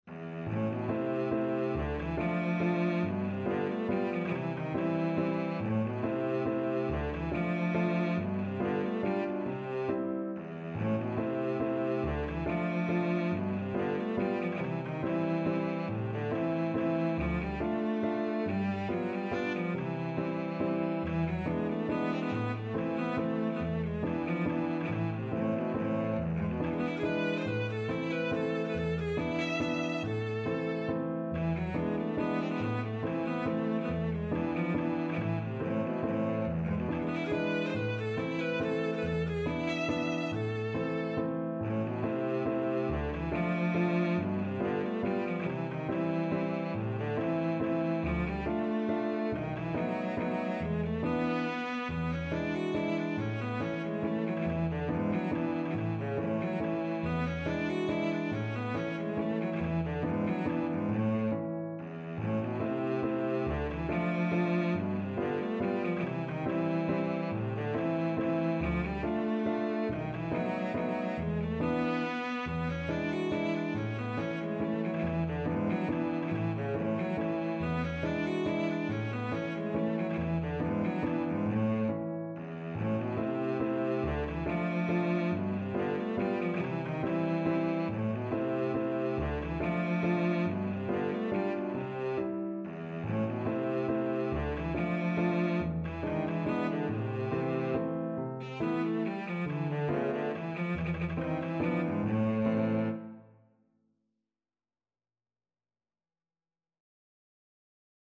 Free Sheet music for Cello
Cello
A minor (Sounding Pitch) (View more A minor Music for Cello )
Allegretto = 140
3/4 (View more 3/4 Music)
Classical (View more Classical Cello Music)